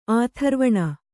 ♪ ātharvaṇa